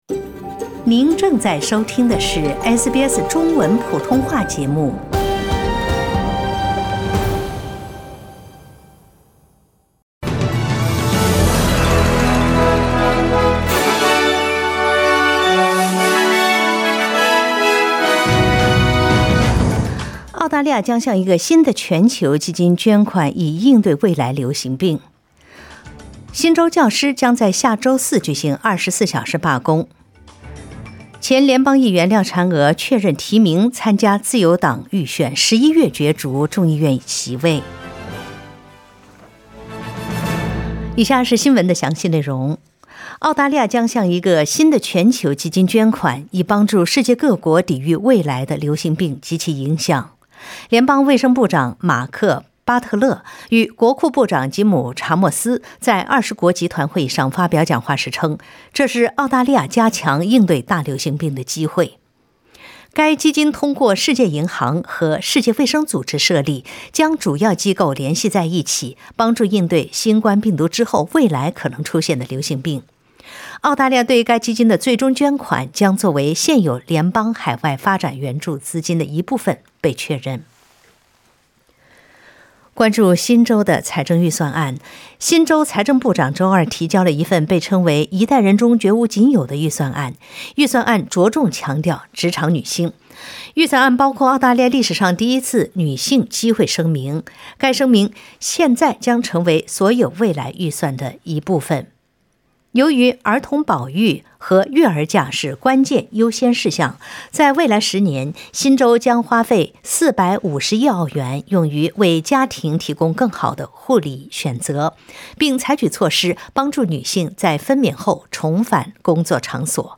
SBS早新闻（6月22日）
SBS Mandarin morning news Source: Getty Images